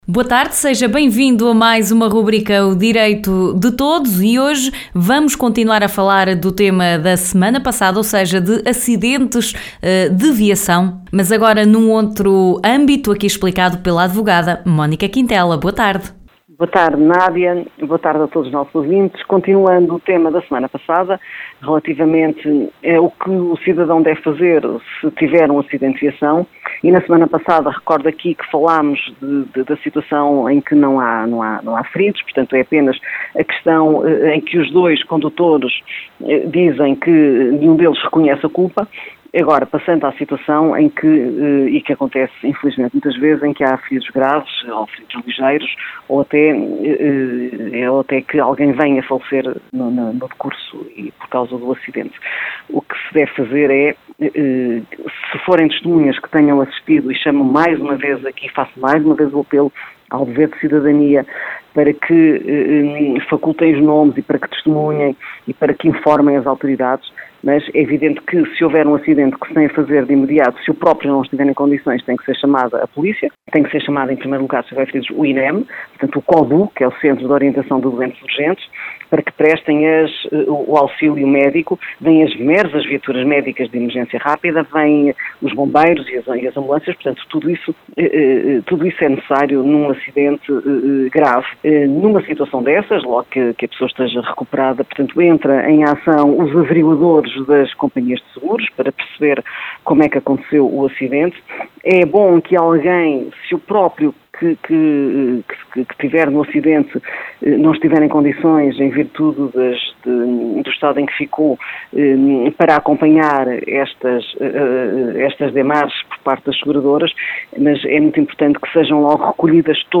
Hoje a advogada Mónica Quintela termina o tema “acidentes de viação, o que fazer?”, focando-se nos casos em que deles resultam vítimas.